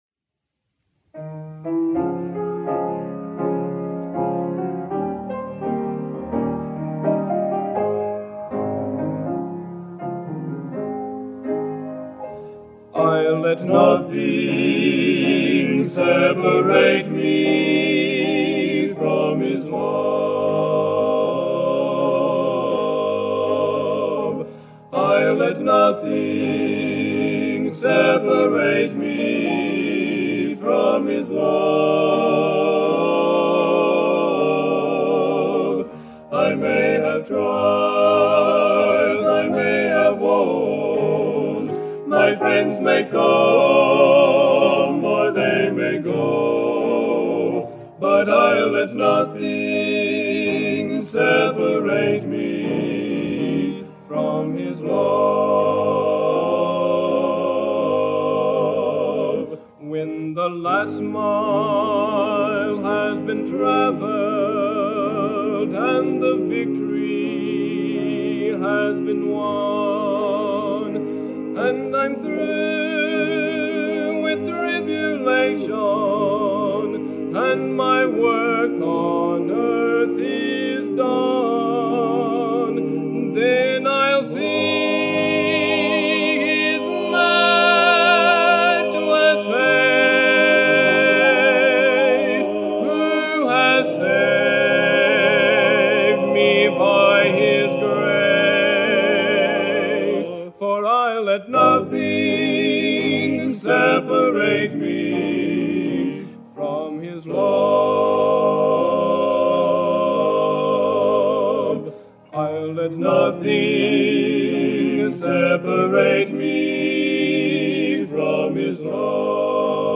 This recording is of the 1963 Bethany Nazarene College Collegiate Quartet, members unknown. The recordings come from a 1963 audio tape reel, and were transferred from the master 1/4" magnetic audio tape.